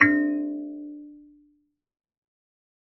kalimba2_wood-D3-pp.wav